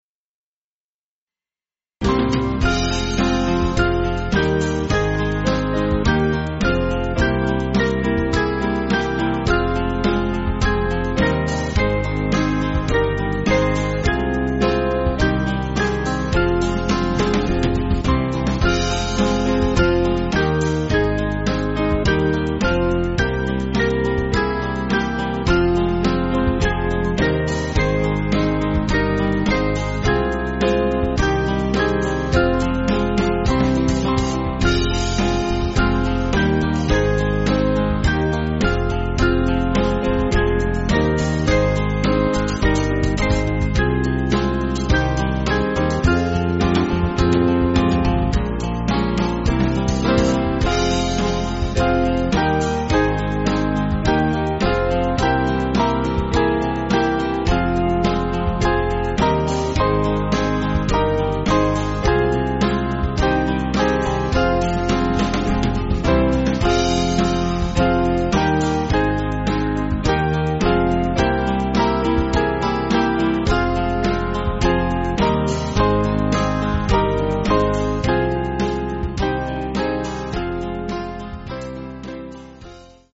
Small Band
(CM)   5/Fm